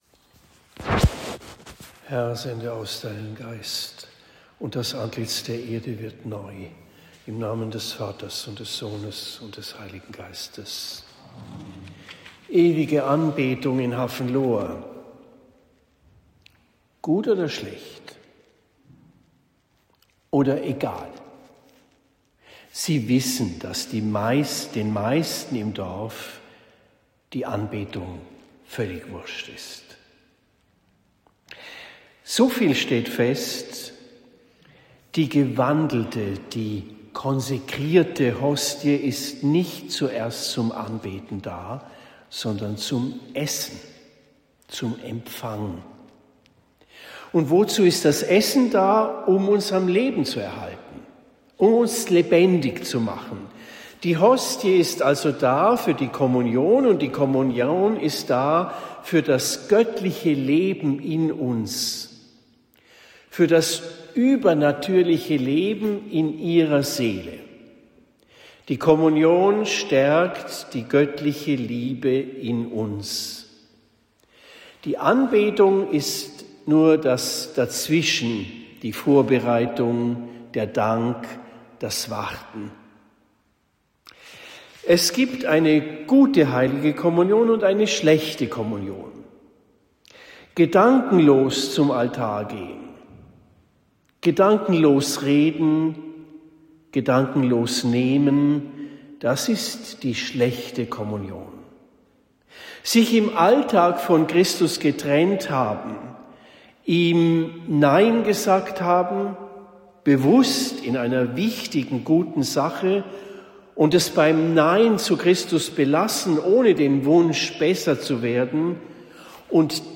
Predigt in Hafenlohr am 21. Jänner 2026